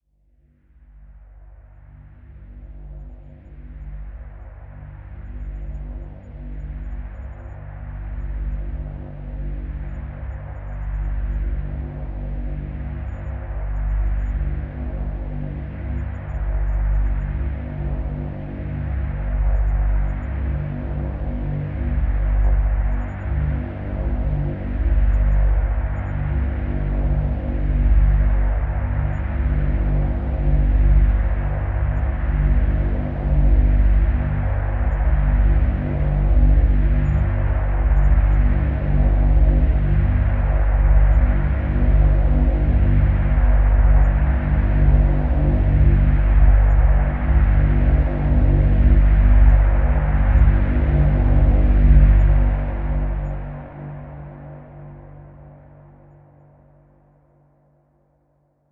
当鼓声响起时，你可以想象一个人的恐惧突然成真，你被追赶或逃跑，试图摆脱不愉快的事情。
标签： 恐惧 凶手 杀人 戏剧 电影 谋杀 紧张 案件
声道立体声